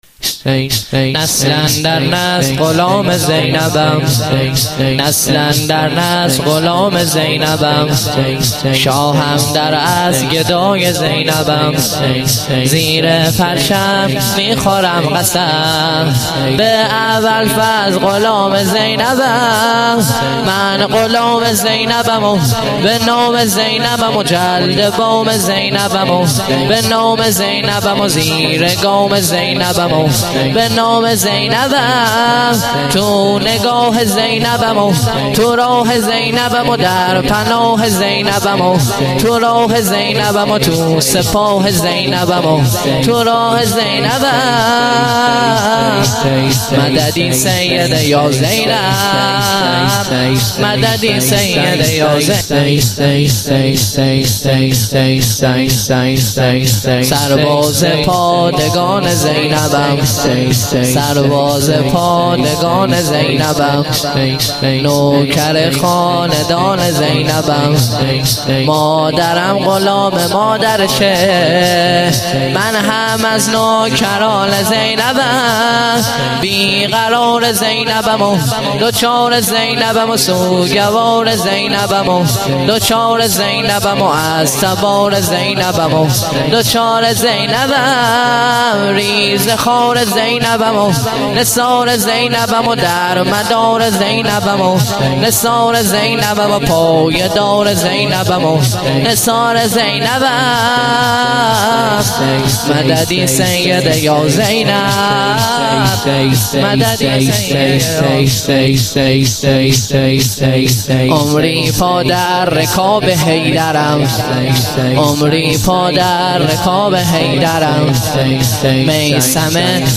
شور - نسل اندر نسل غلام زینبم